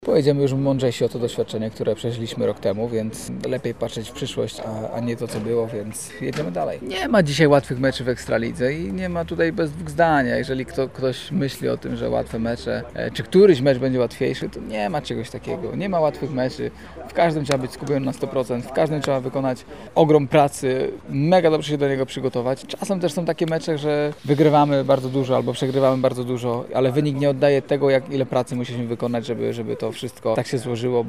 mówił lubelski zawodnik podczas Press&Practise Day